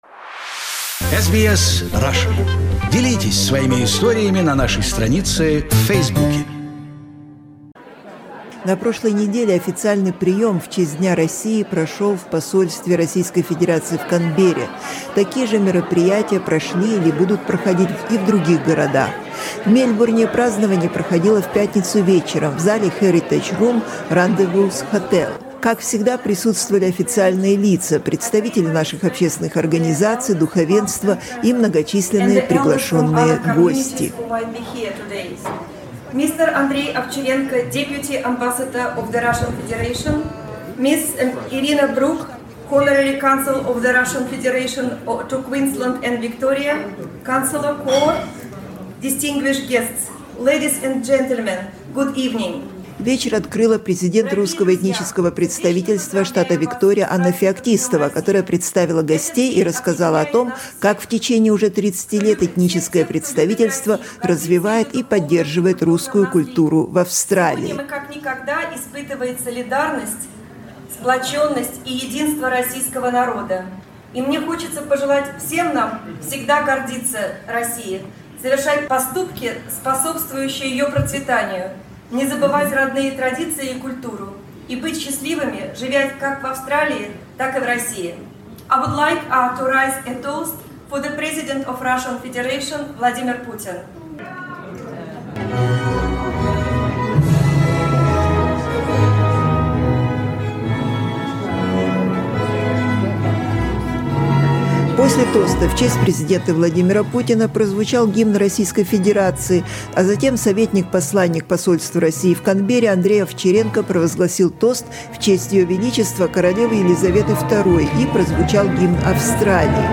The celebration of Russian National Day in Melbourne was hosted by Russian Ethnic Representative Council of Victoria last Friday with the Cocktail Reception at Heritage Lounge in Rendezvous Hotel. It was an opportunity to get together and celebrate the occasion in the company of Russian and Australian diplomats, members of State government and Parliament of Victoria, community leaders, representatives from Russian and Australian business and art circles.